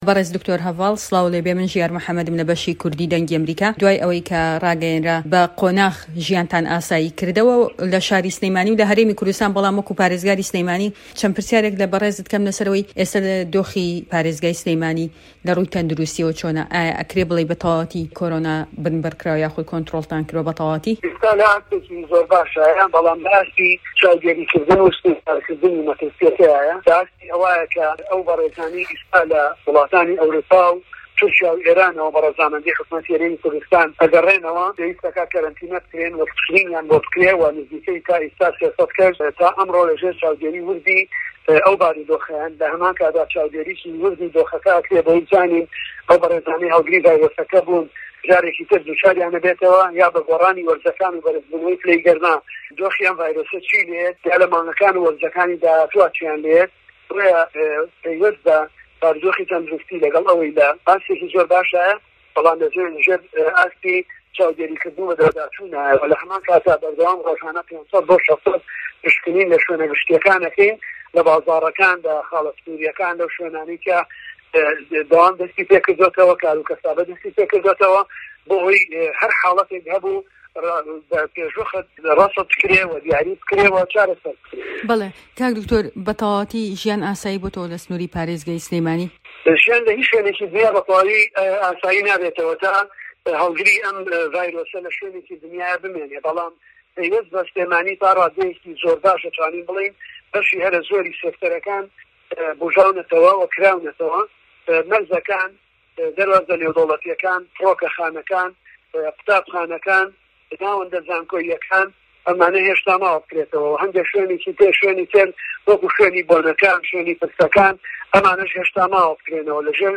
ده‌قی وتووێژه‌كه‌